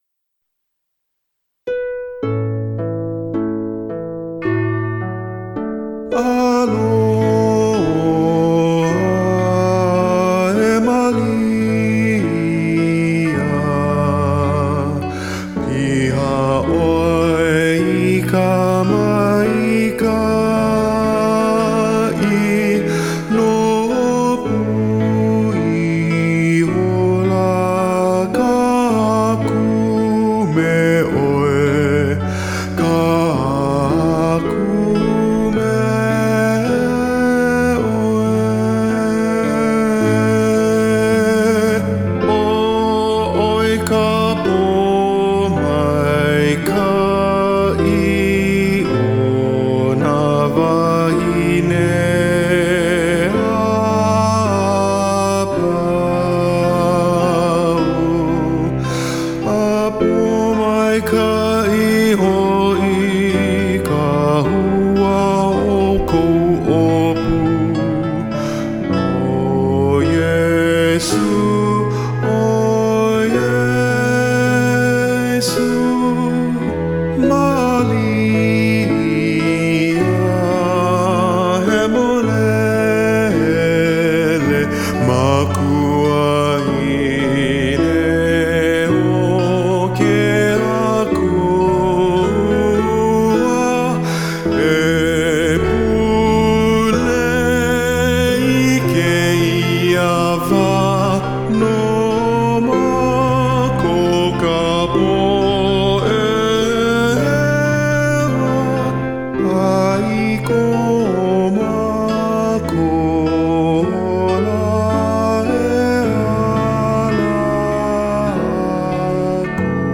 Instrumental | Downloadable